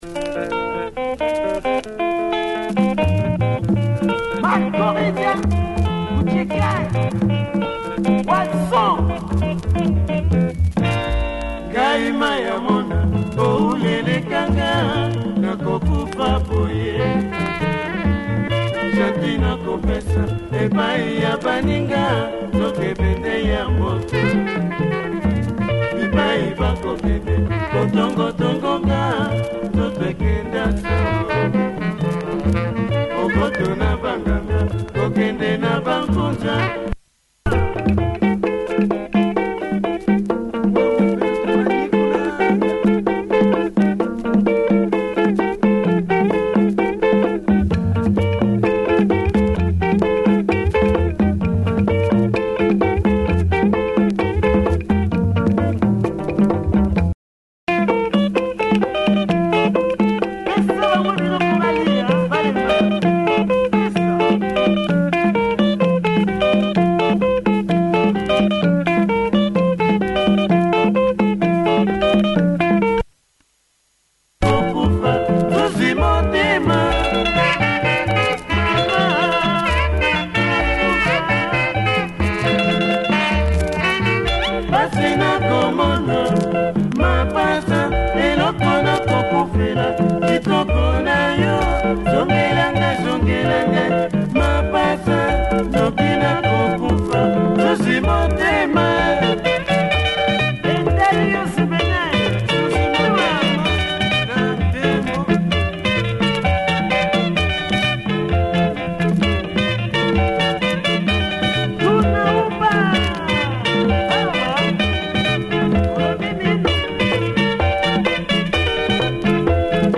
Nice Congo track, check audio of both sides. https